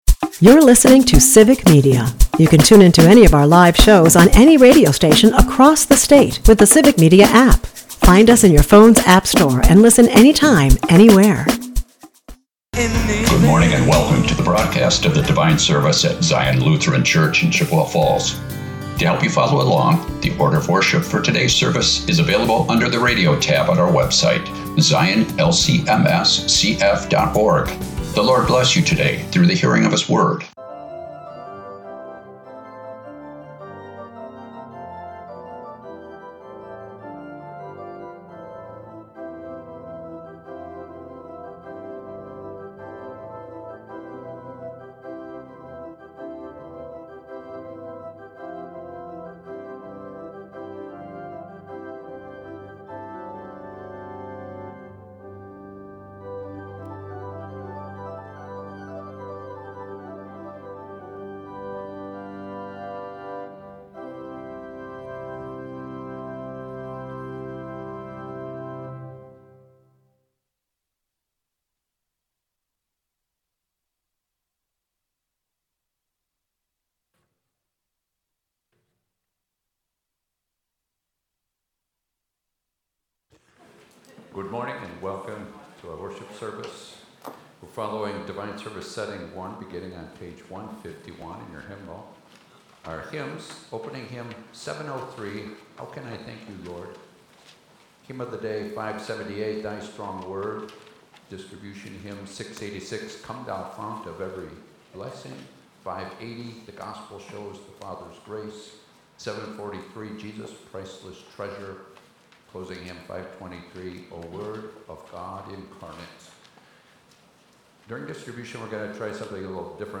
Sunday Service - Civic Media